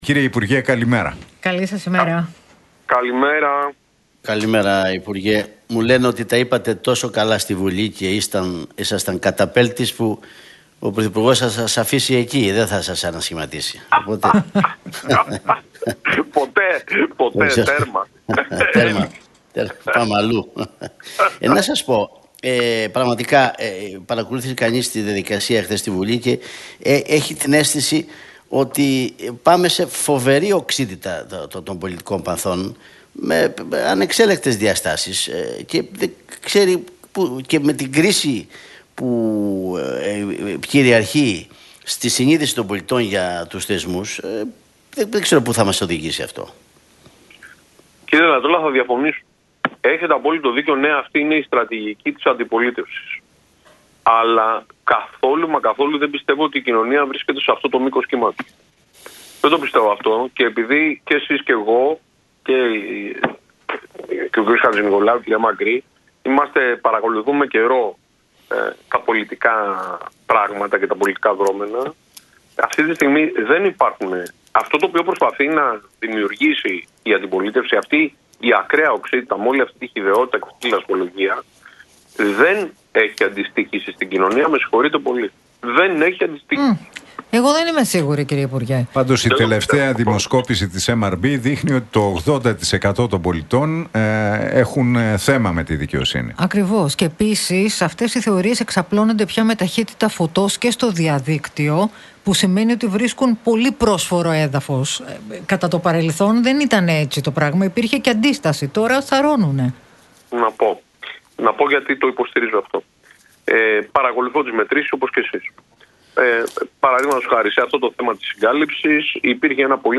Για την τραγωδία των Τεμπών και τα όσα διαδραματίστηκαν χθες στη Βουλή μίλησε ο υπουργός Επικρατείας, Μάκης Βορίδης στον Realfm 97,8 και τους Νίκο Χατζηνικολάου